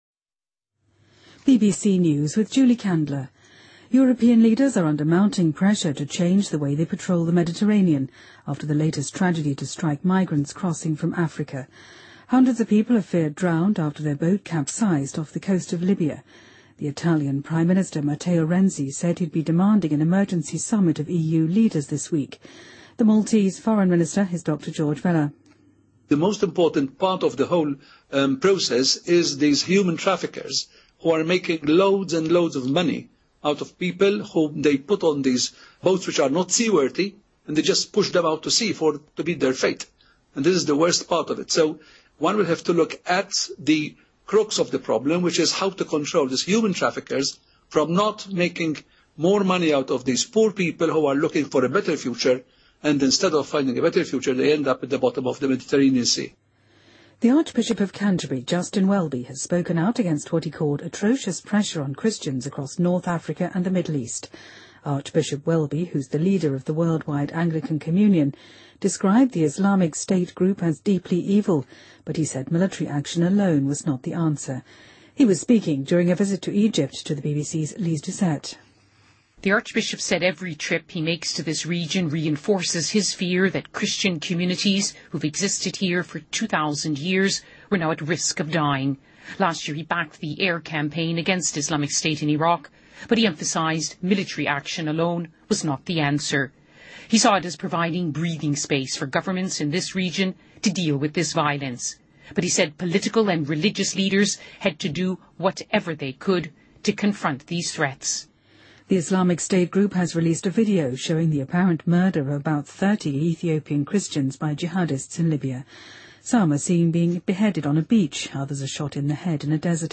日期:2015-04-20来源:BBC新闻听力 编辑:给力英语BBC频道